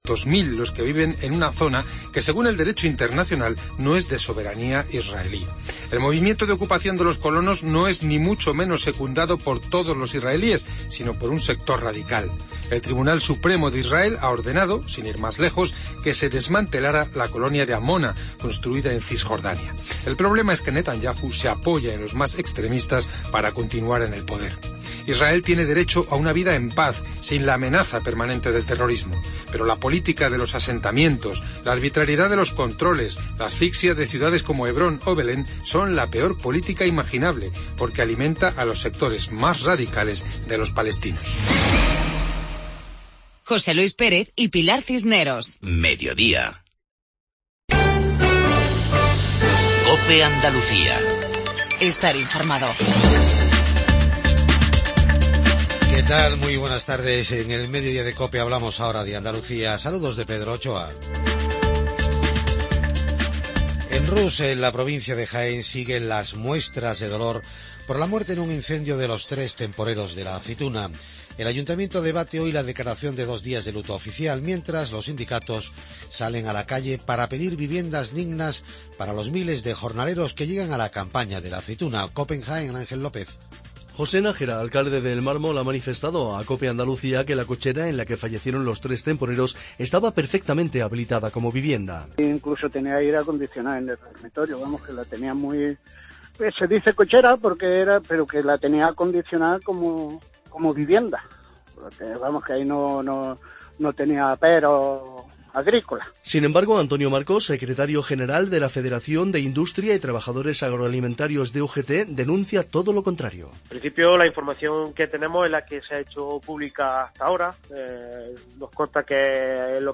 INFORMATIVO REGIONAL MEDIODIA